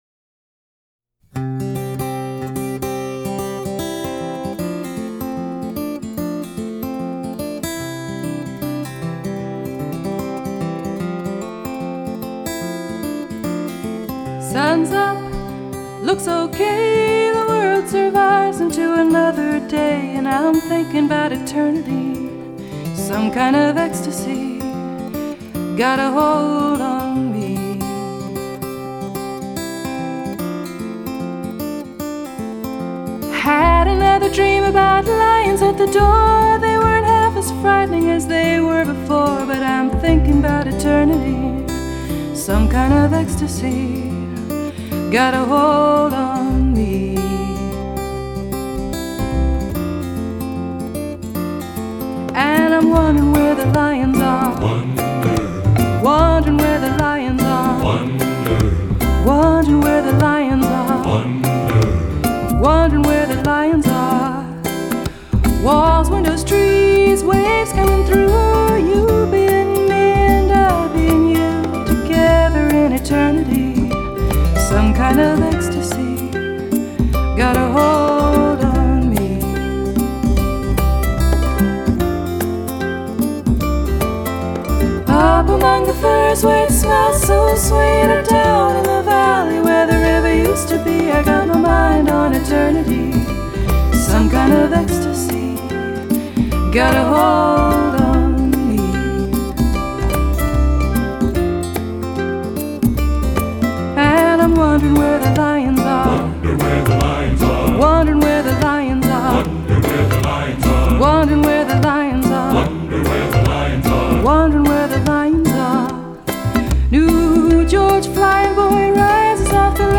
the vocal group backing her